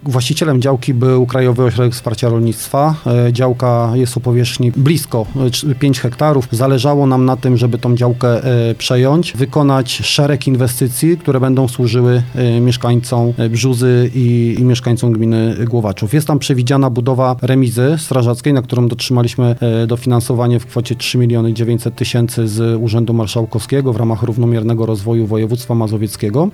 Komentuje wójt Głowaczowa Hubert Czubaj: